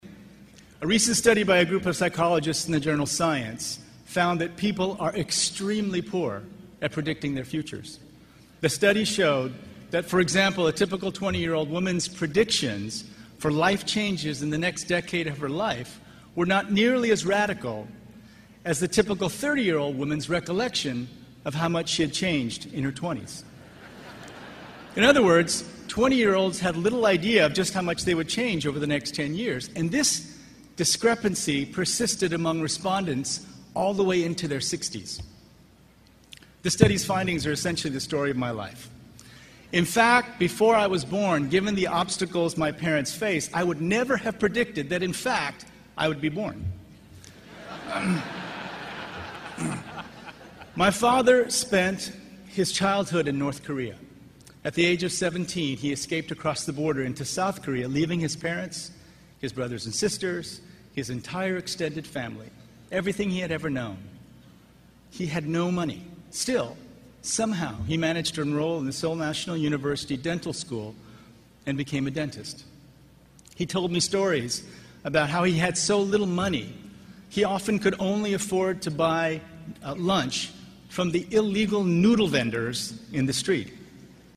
公众人物毕业演讲 第63期:金墉美国东北大学(2) 听力文件下载—在线英语听力室